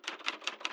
terminal_text_light.wav